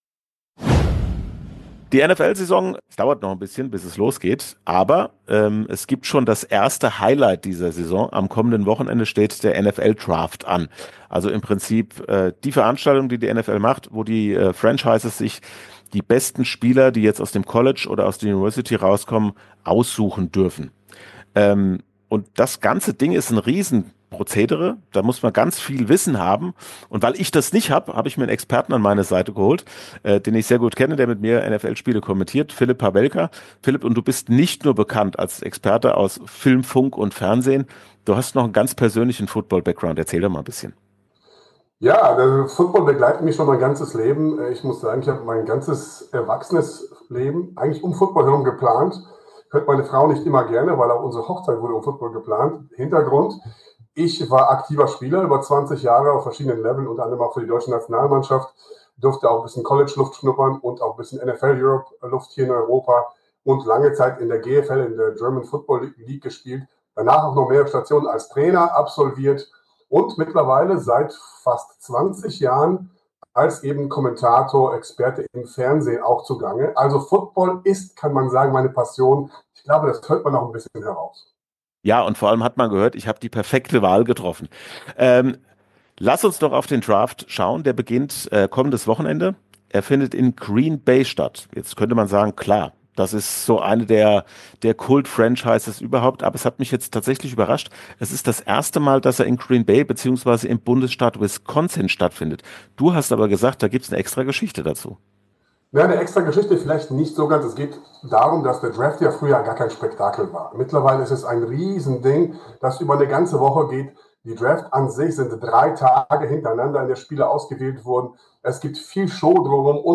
Interviews in voller Länge